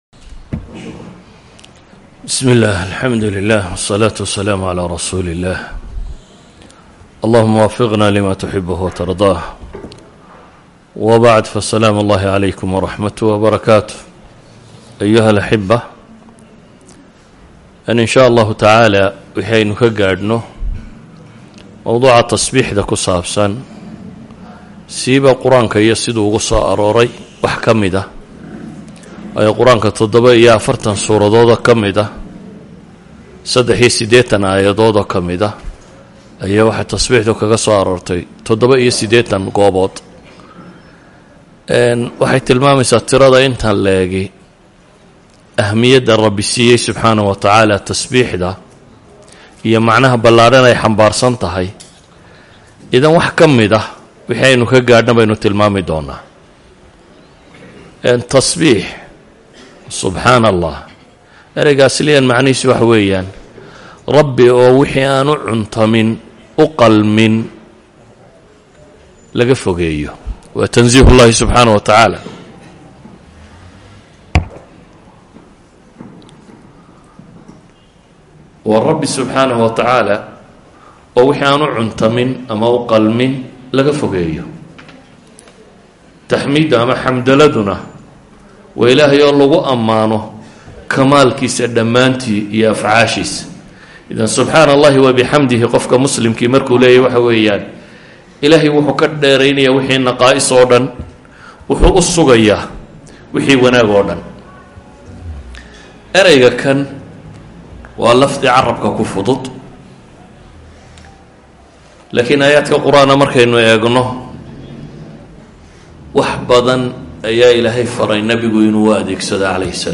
Genre: Muxaadaro.